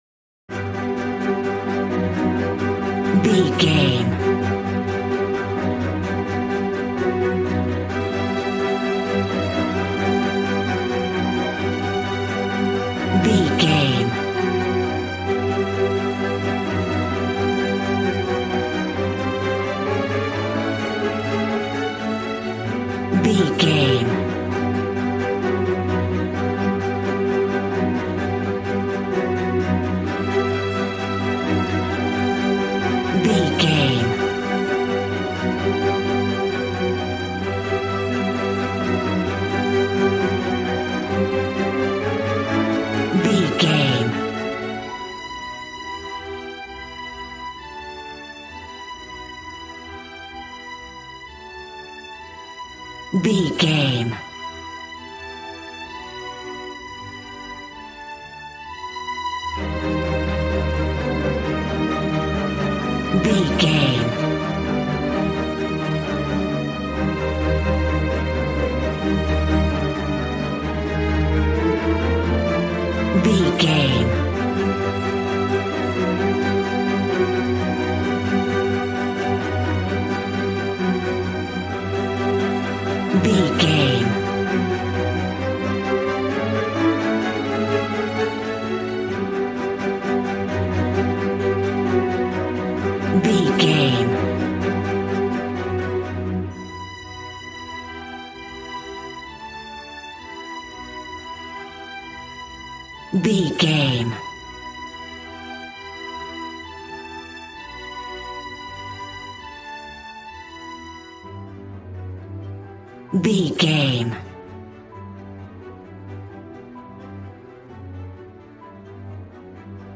Regal and romantic, a classy piece of classical music.
Ionian/Major
regal
cello
violin
strings